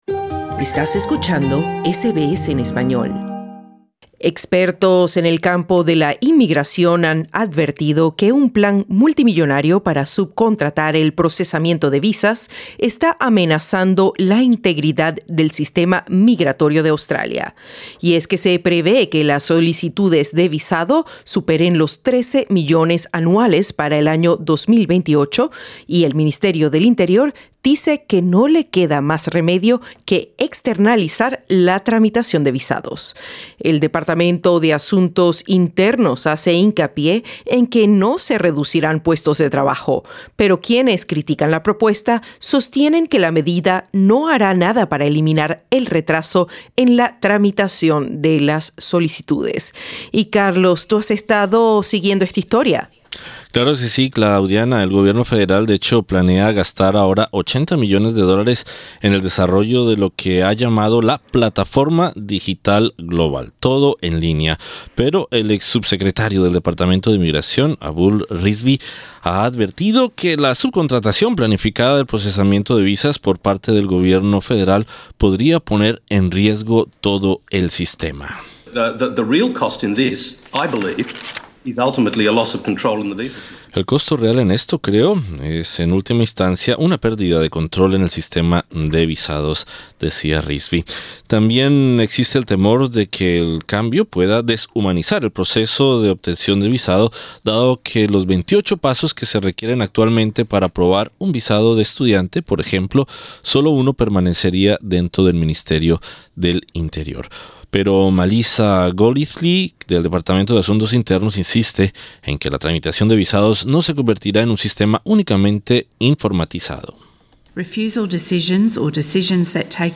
conversamos con el agente migratorio en Sídney